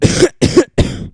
cough.wav